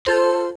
Index of /phonetones/unzipped/LG/A200/Keytone sounds/Sound2